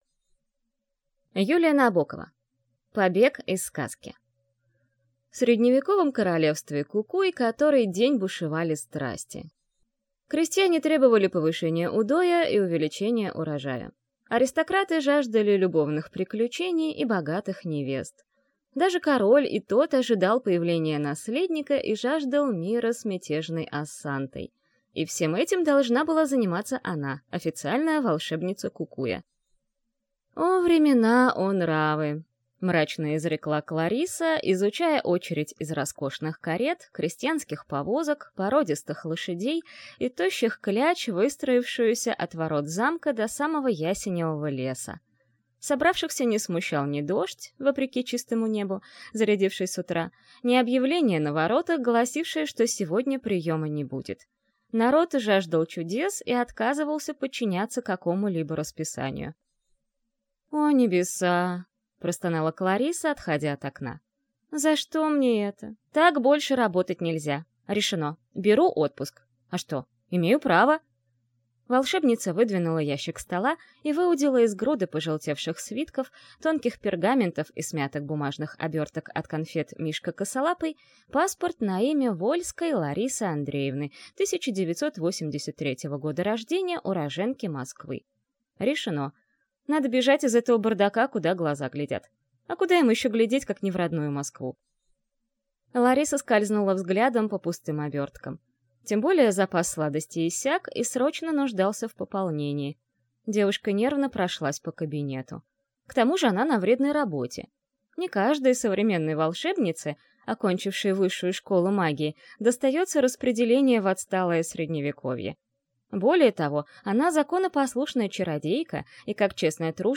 Аудиокнига Побег из сказки | Библиотека аудиокниг
Прослушать и бесплатно скачать фрагмент аудиокниги